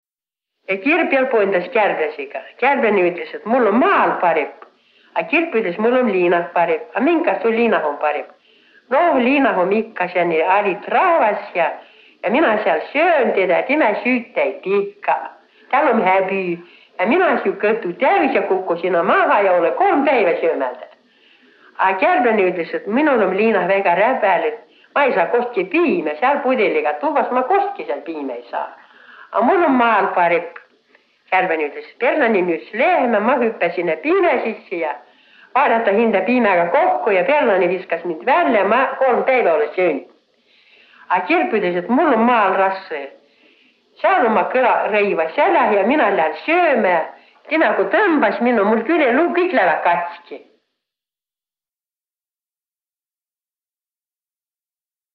Jutt “Kirp ja kärbläne - liinaelo ja maaelo”
Peri plaadi päält “Juttõ ja laulõ seto aabitsa mano”.